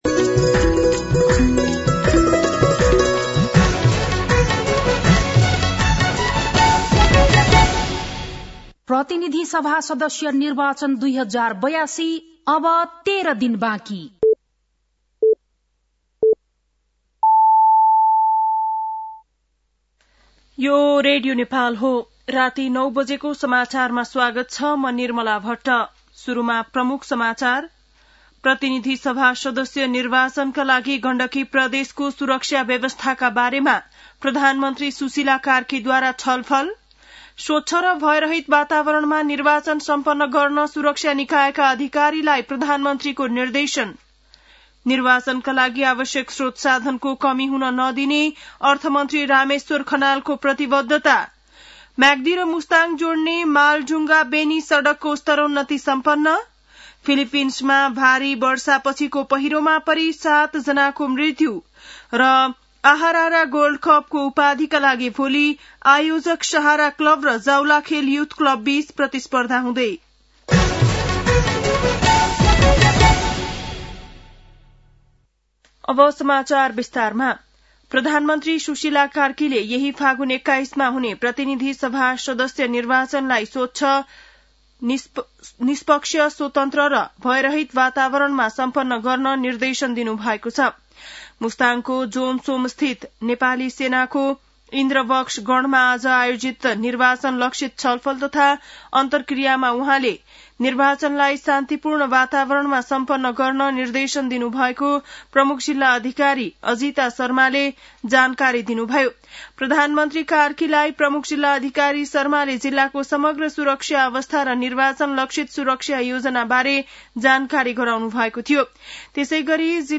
बेलुकी ९ बजेको नेपाली समाचार : ८ फागुन , २०८२
9-pm-nepali-news-11-08.mp3